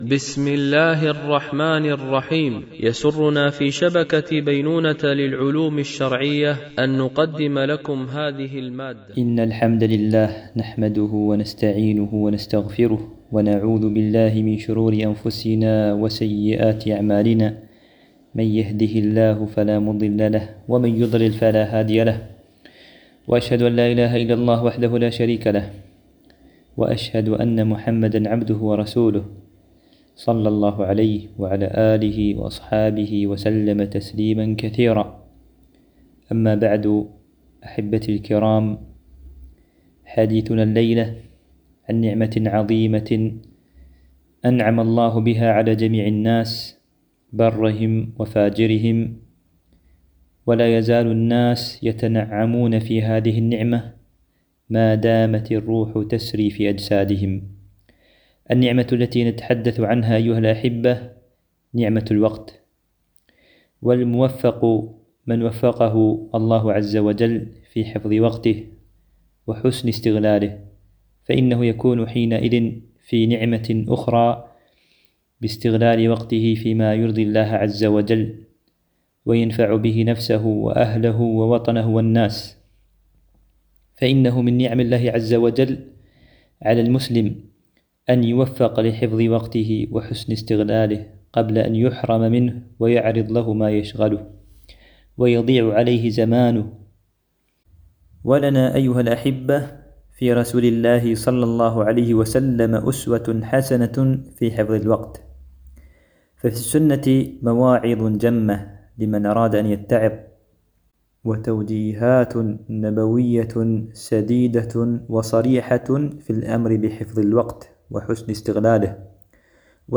MP3 Mono 44kHz 128Kbps (VBR)